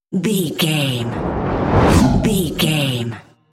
Airy whoosh pass by large
Sound Effects
futuristic
pass by
sci fi